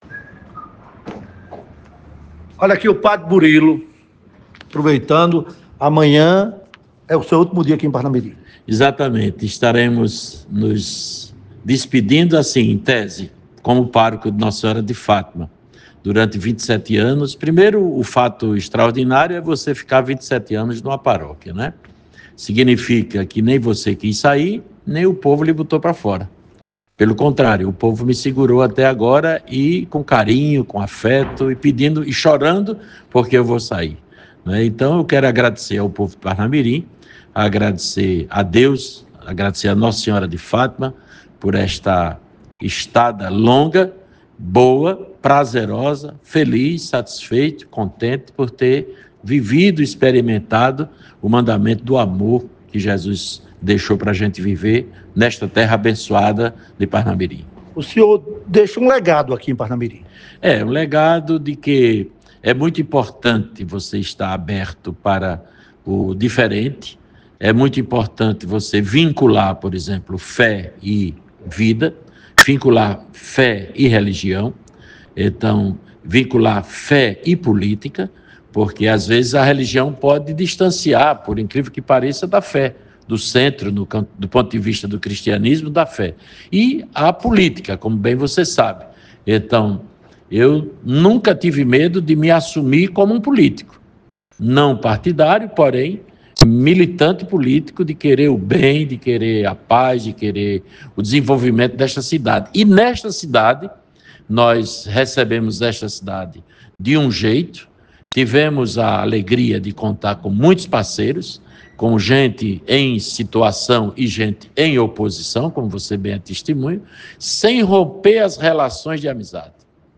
Em pronunciamento emocionado